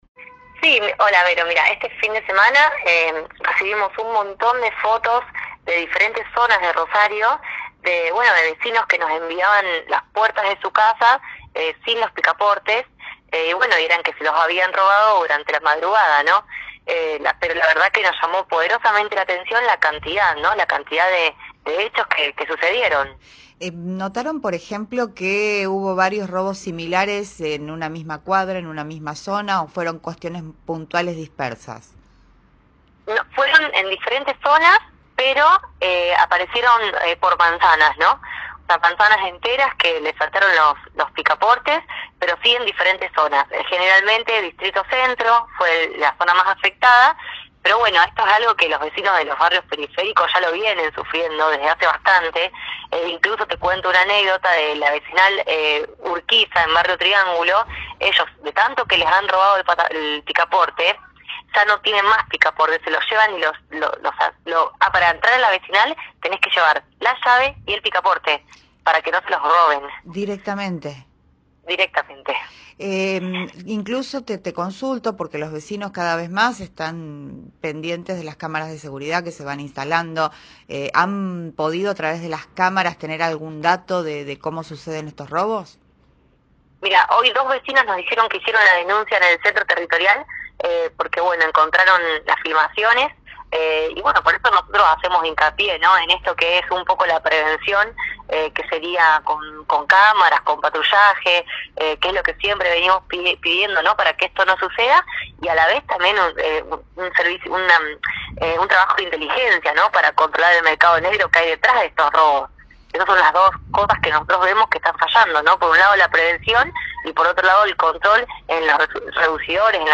En diálogo con Primera Plana de Cadena 3 Rosario